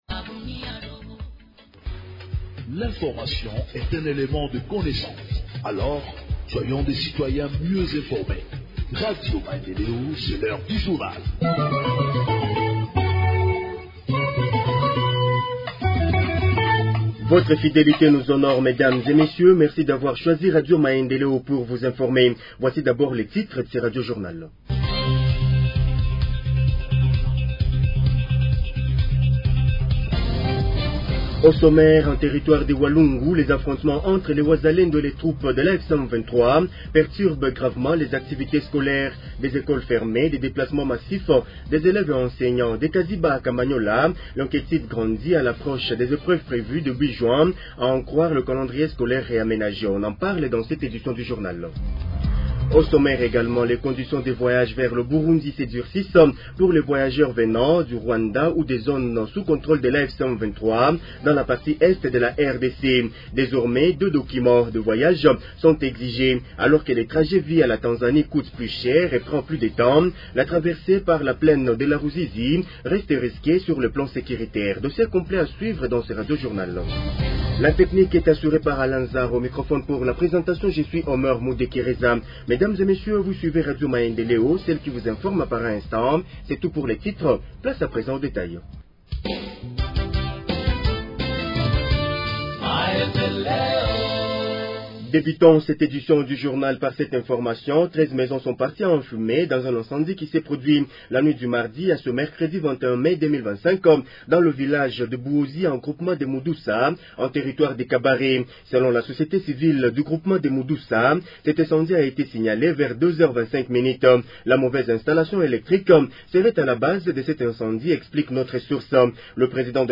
Journal en Français du 22 Mai 2025 – Radio Maendeleo